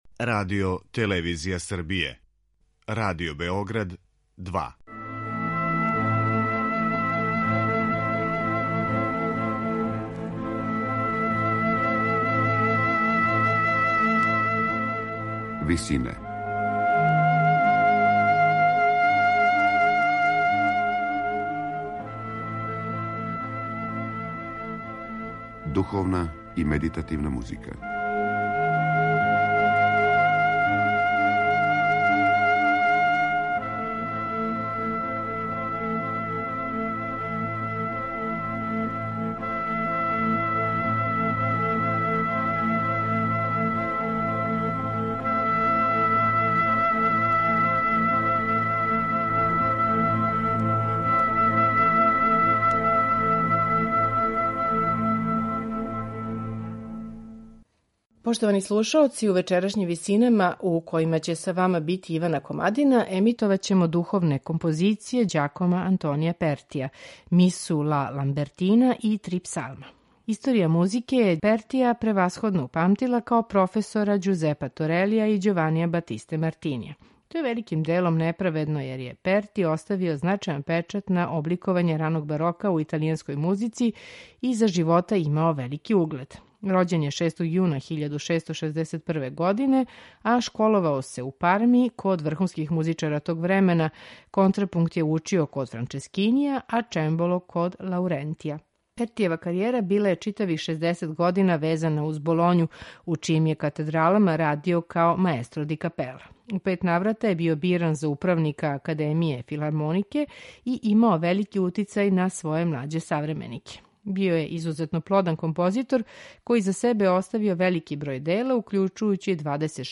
сопран
алт
бас